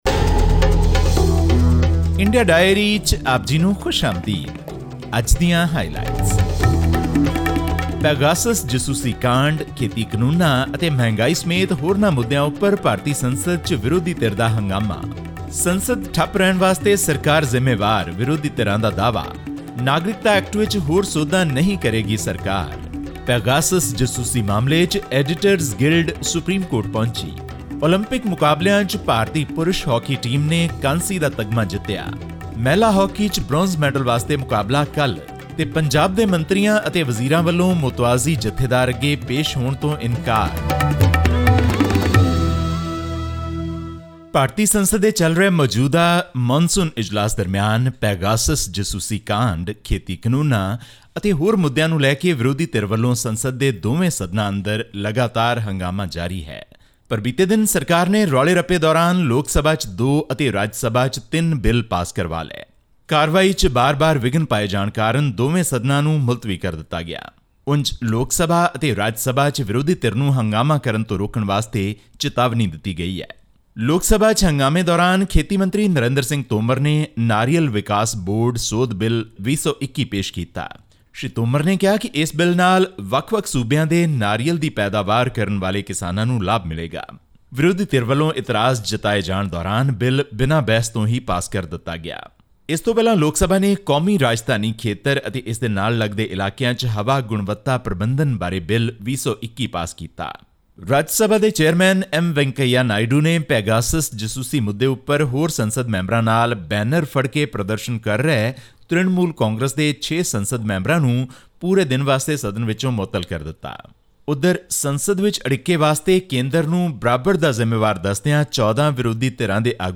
The Indian team led by captain Manpreet Singh overpowered Germany by winning 5-4 in a thrilling bronze medal match to add to India's tally at the Tokyo 2020 Olympics. All this and more in our weekly news segment from India.